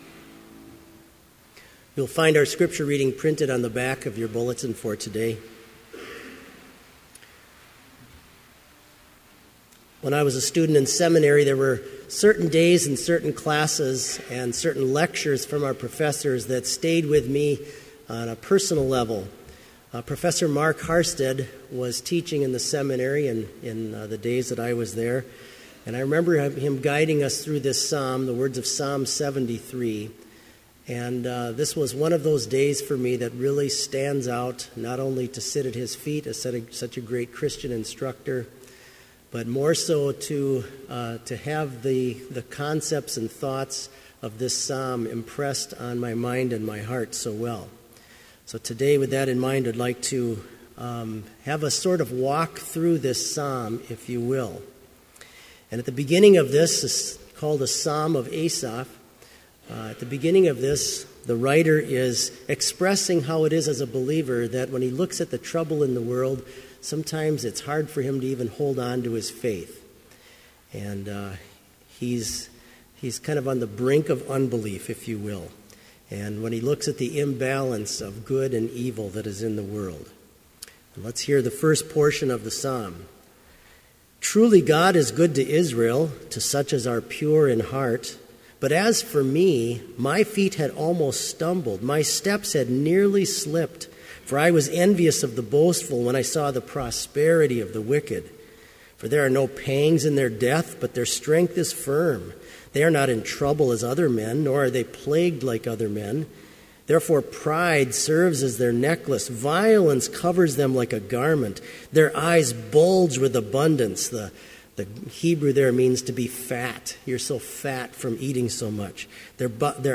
Complete Service
• Prelude
• Homily
This Chapel Service was held in Trinity Chapel at Bethany Lutheran College on Friday, February 27, 2015, at 10 a.m. Page and hymn numbers are from the Evangelical Lutheran Hymnary.